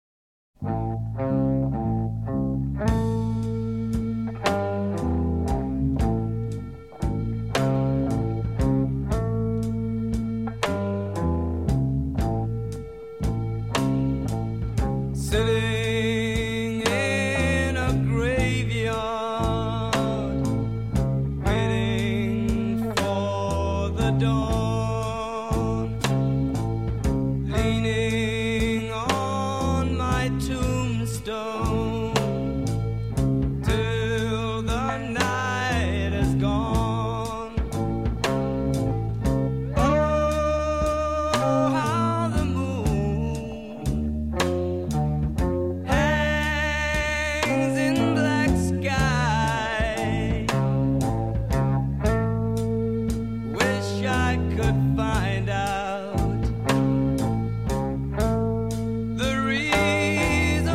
Рок
специализировавшихся на блюз-роке.